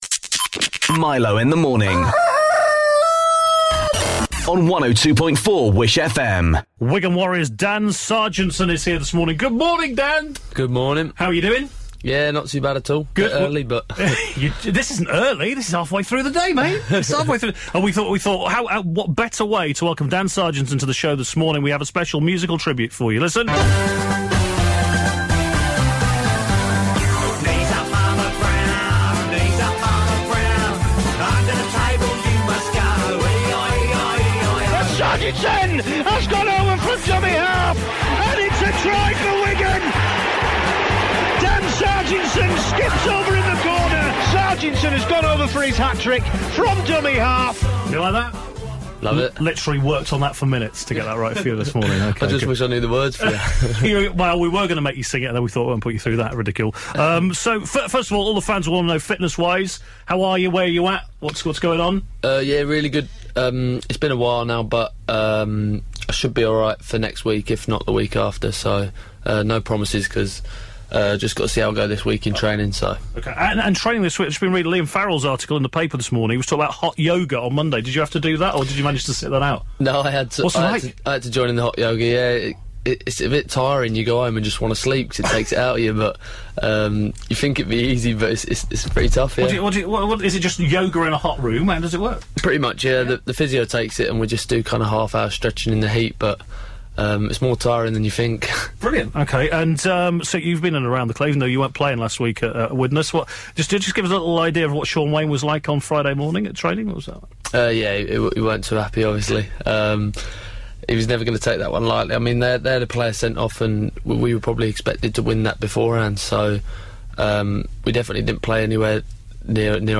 Listen back to Wigan Warriors' star Dan Sarginson co-presenting